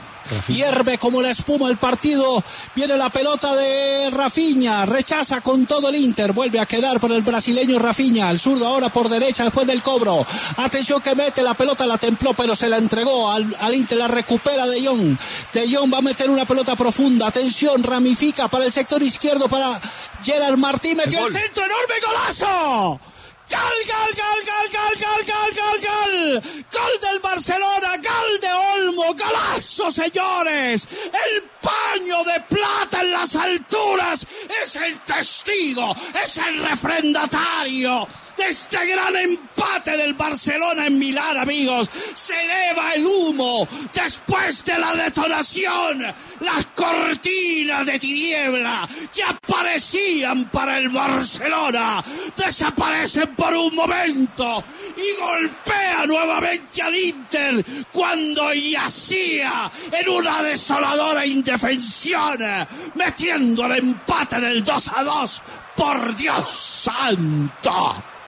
“El paño de plata es el testigo de este gran empate”: Martín de Francisco narró el gol del Barca
Así fue la narración de Martín de Francisco del gol del Barcelona: